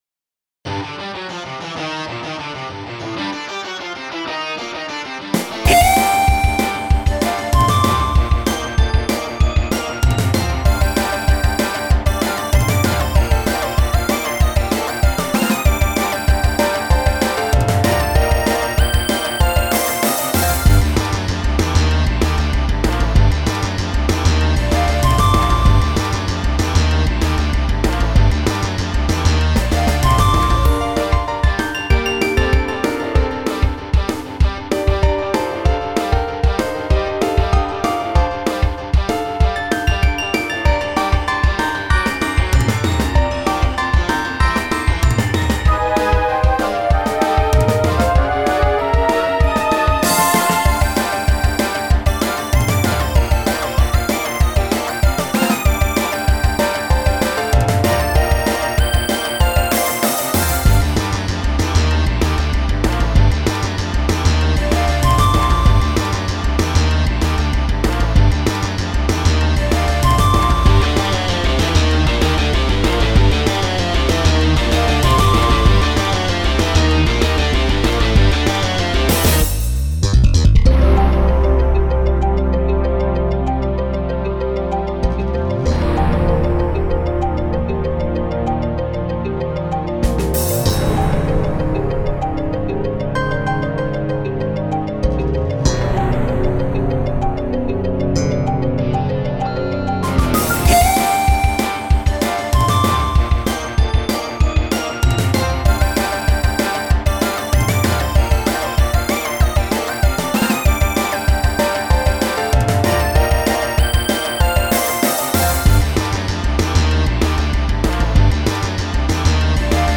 戰鬥配樂。